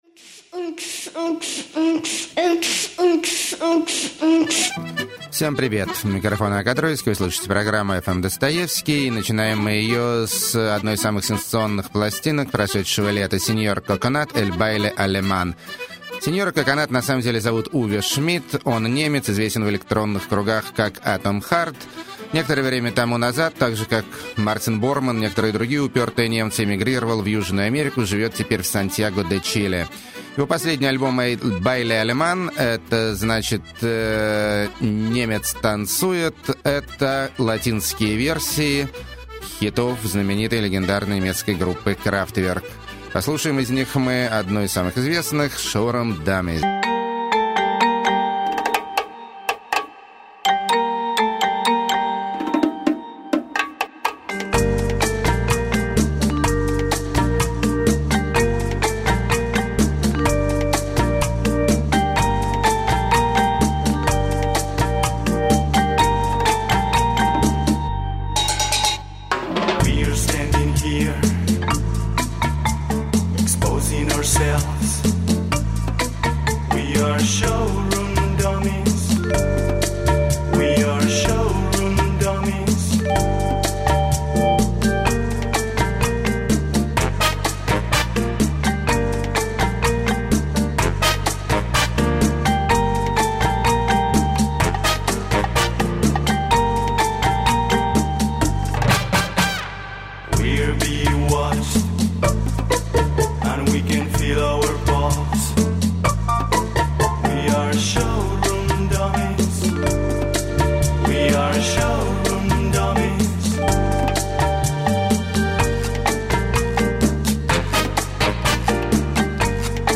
Девичий Диско – Lounge.
Нежнейший Surf-поп.
Блестящий Альбом Ремиксов Секс-футуризма 60-х.
Кантри-блюз Для Умников И Умниц.